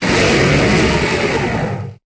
Cri d'Ékaïser dans Pokémon Épée et Bouclier.